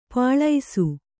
♪ pālaisu